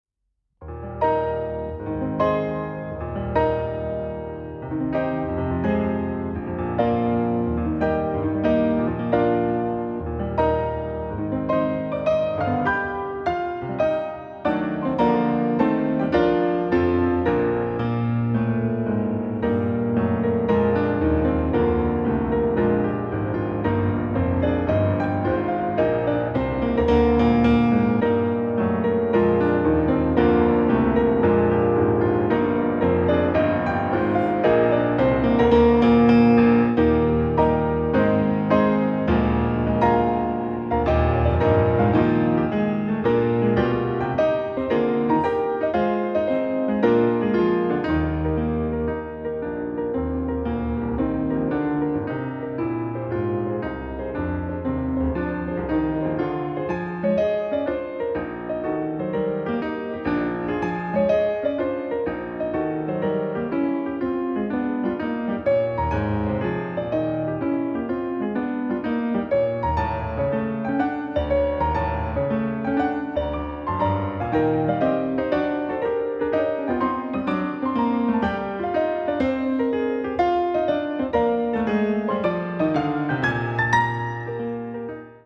piano
Modéré, avec une constante énergie